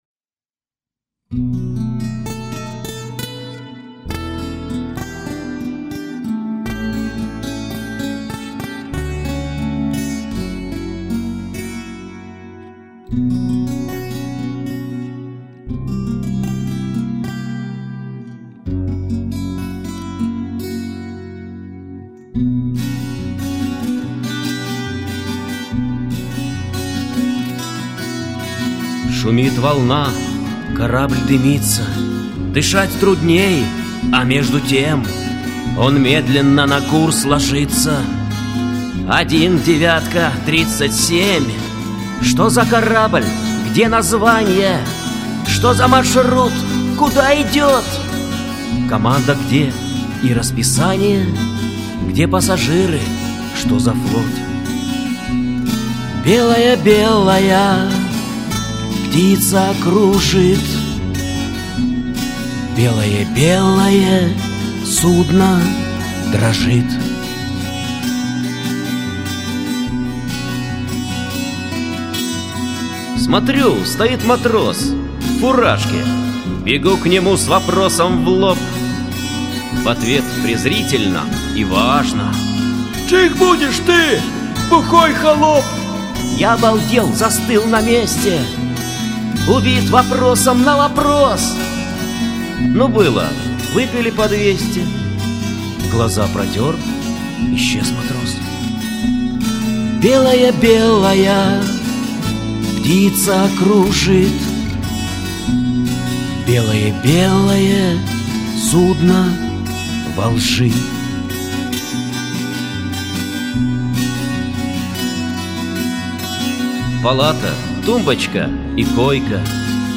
~ Песни под гитару ~